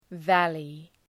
Προφορά
{‘vælı}